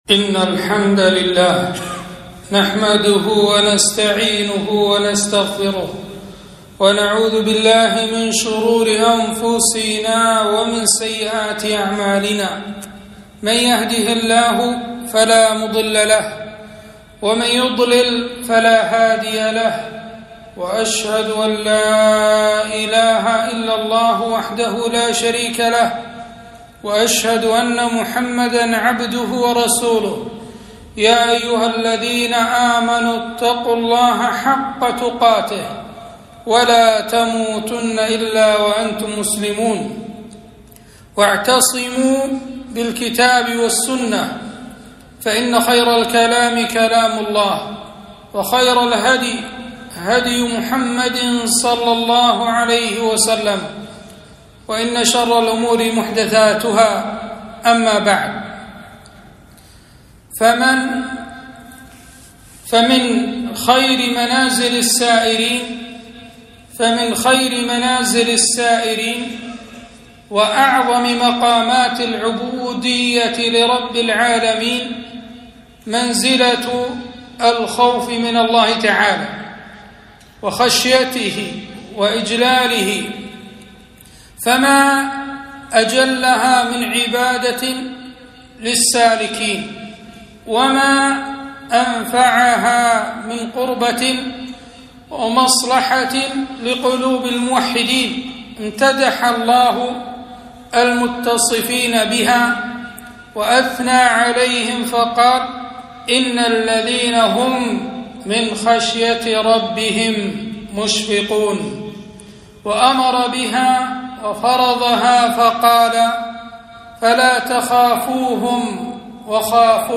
خطبة - التحذير من النار